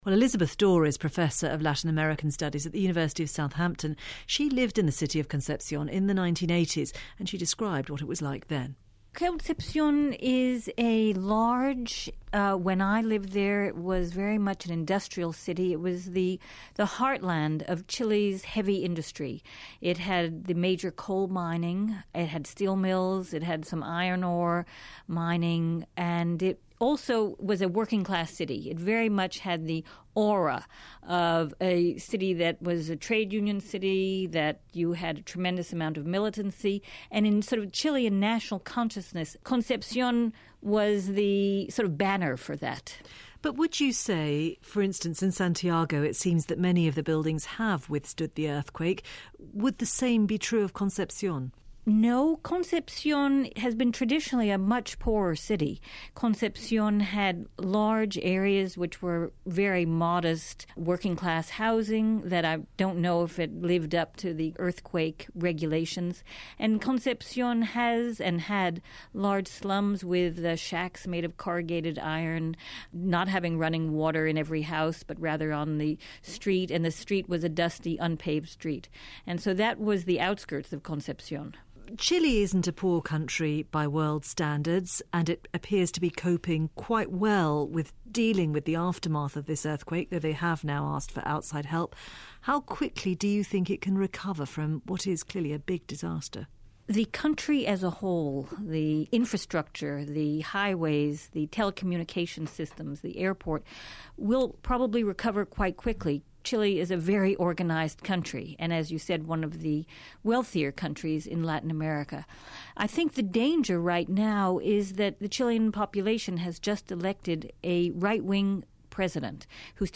Newshour on the World Service interview